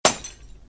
impact.mp3